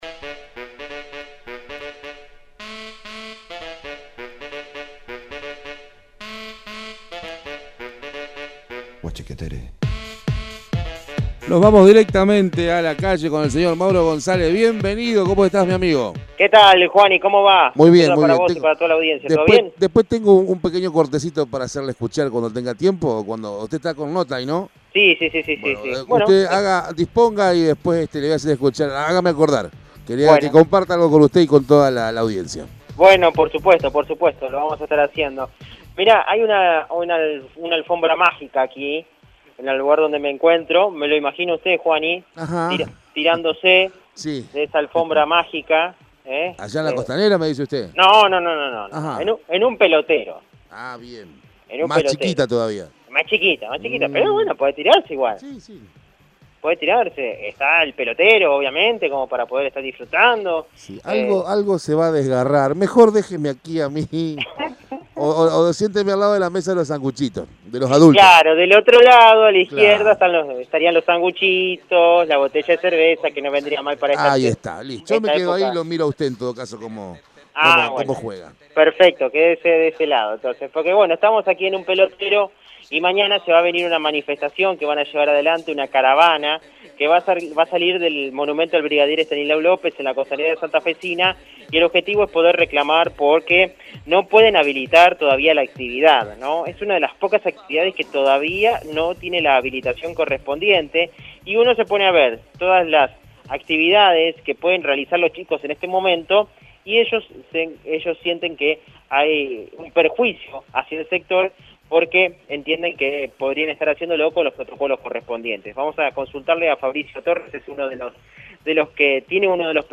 En dialogo con el móvil de Radio EME